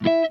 JAZZRAKE 4.wav